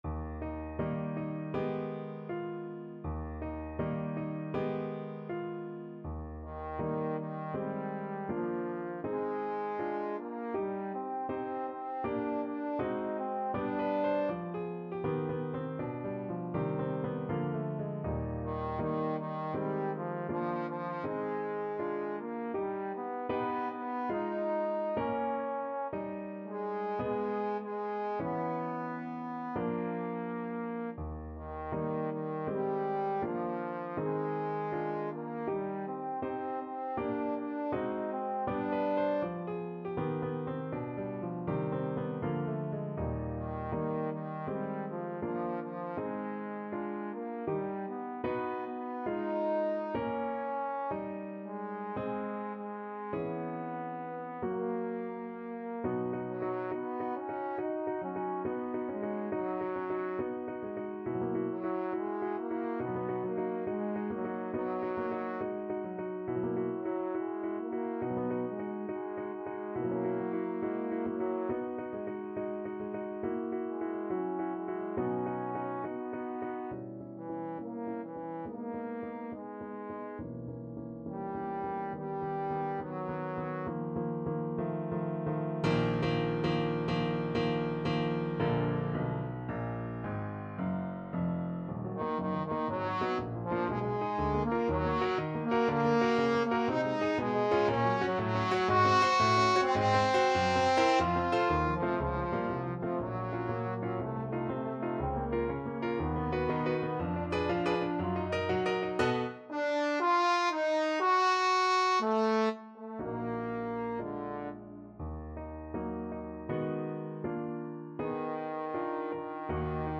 Trombone version
2/4 (View more 2/4 Music)
Moderato =80
Classical (View more Classical Trombone Music)